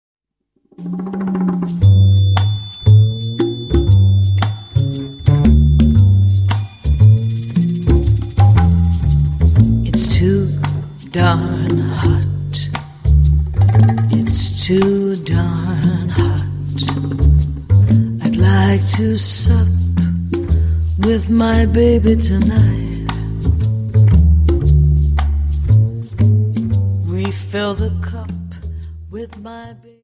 Dance: Slowfox